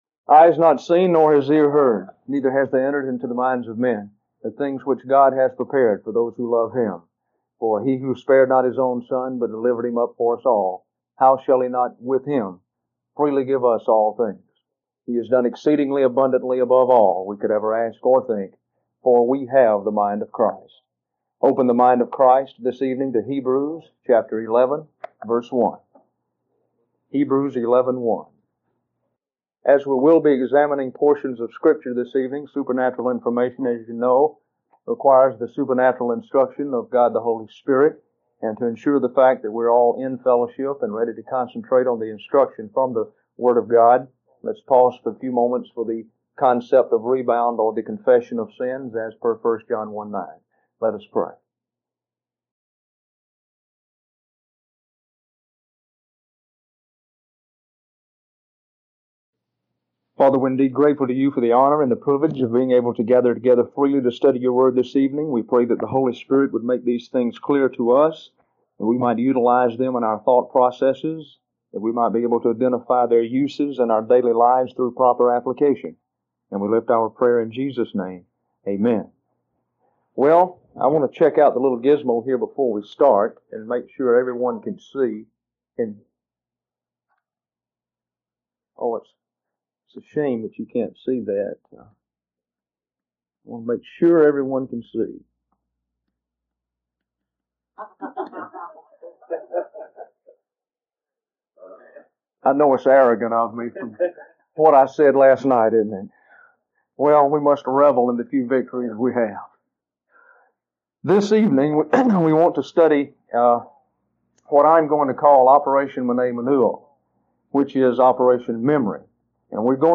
Sermon: Operation Mnemoneuo: Lesson 1